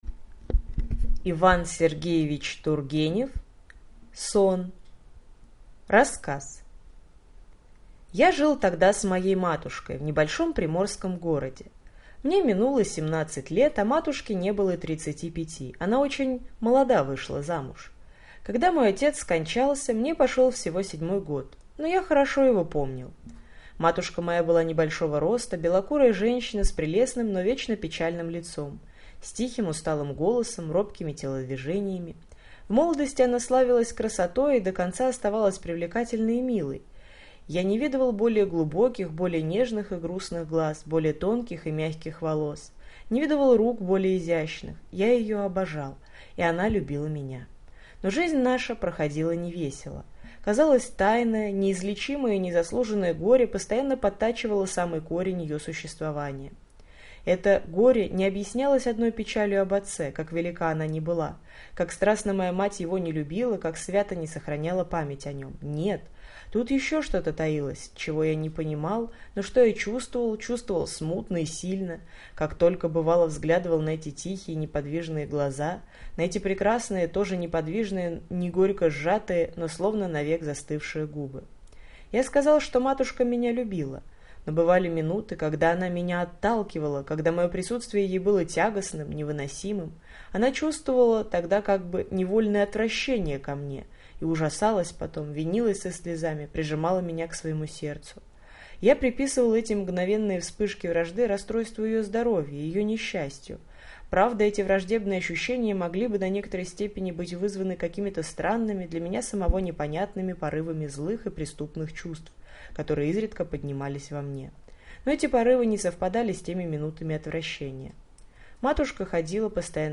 Aудиокнига Сон